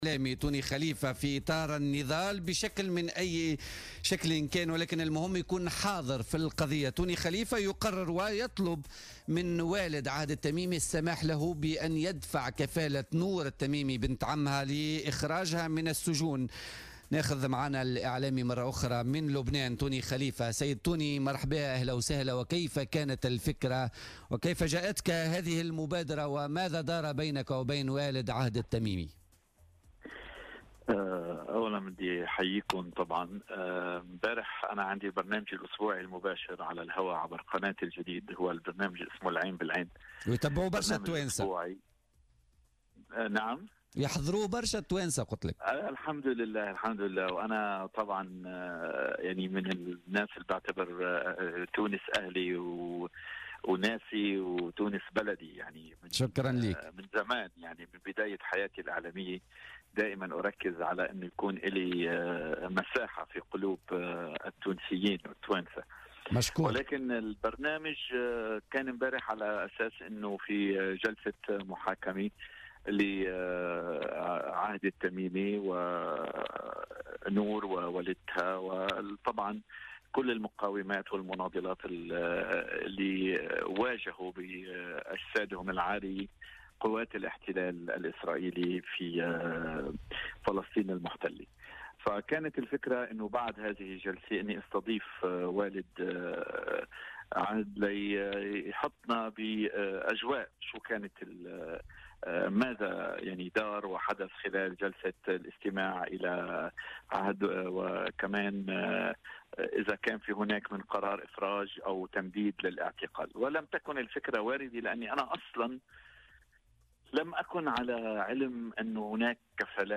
أكد الإعلامي اللبناني طوني خليفة في تصريح خاص للجوهرة اف ام عبر برنامج بوليتيكا...